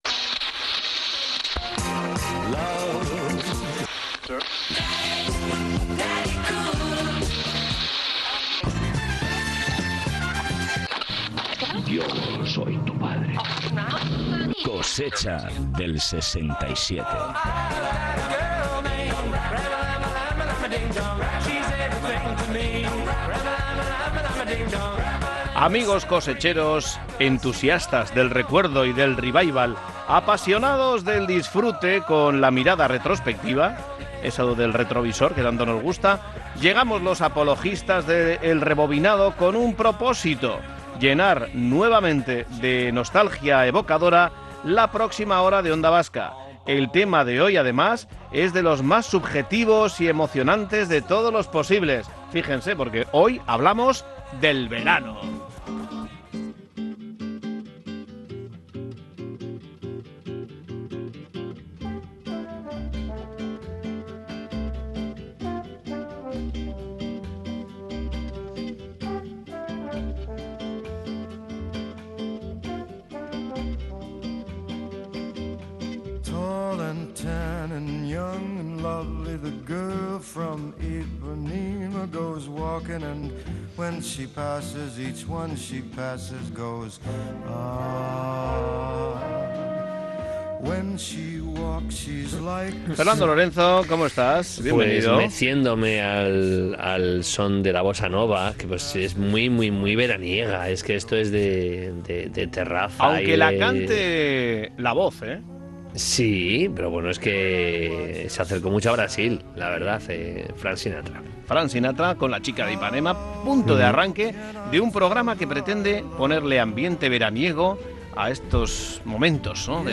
Espacio conectado a la nostalgia a través del humor y la música.